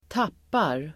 Uttal: [²t'ap:ar]
tappar.mp3